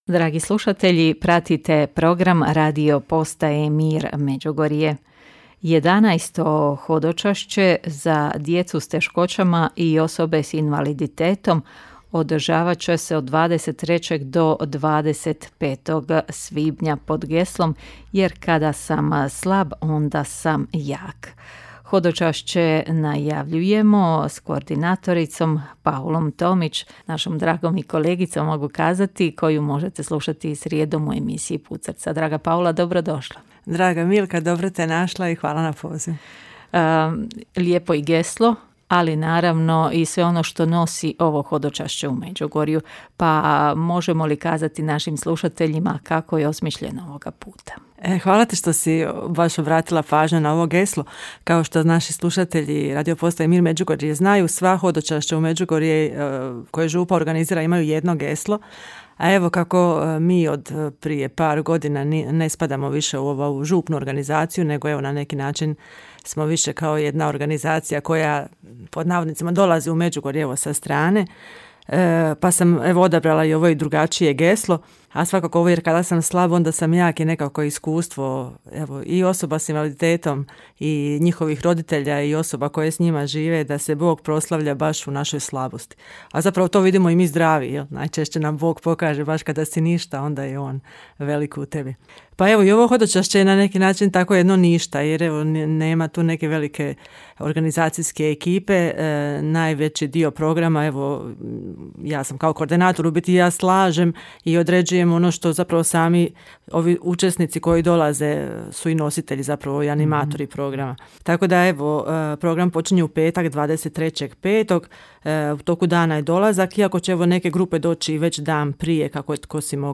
a razgovor poslušajte u audiozapisu.